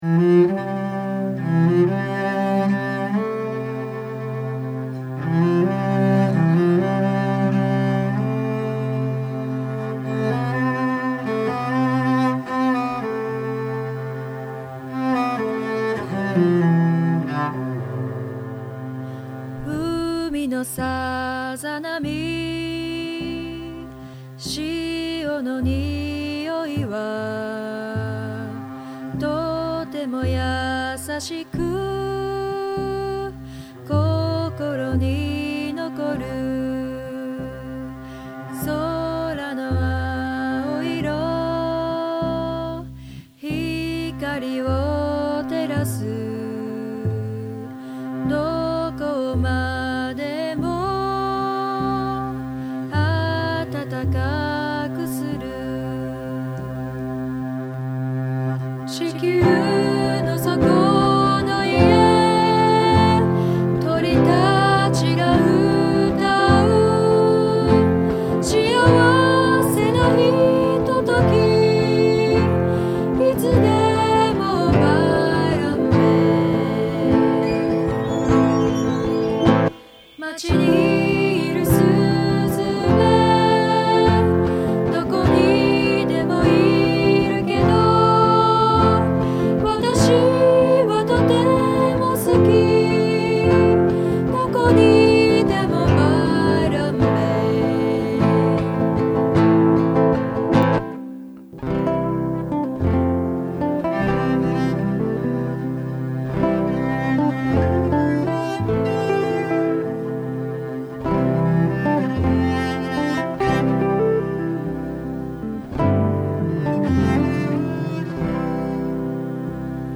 Singing in Japanese was a stroke of brilliance.
Your guest singer this week has a beautiful voice.
This is quiet, simple, and haunting.